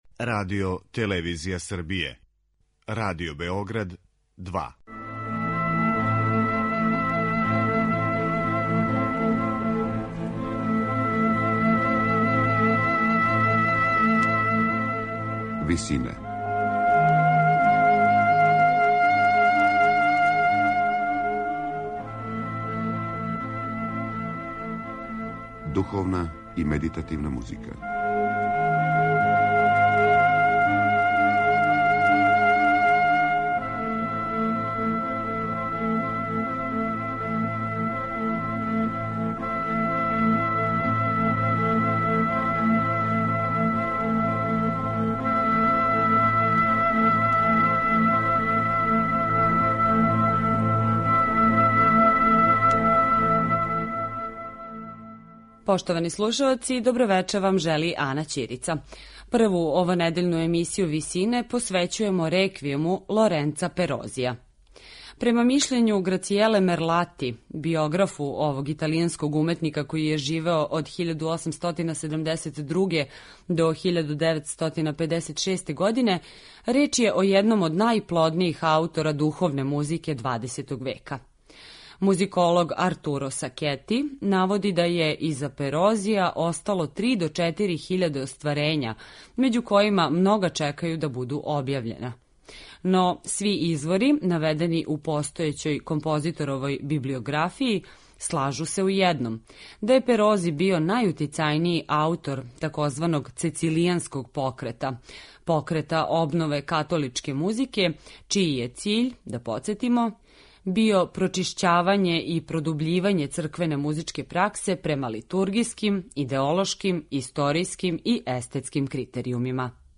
Ораторијум Лоренца Перозија
Емисију духовне и медитативне музике посвећујемо остварењу италијанског уметника Лоренца Перозија (1872-1956).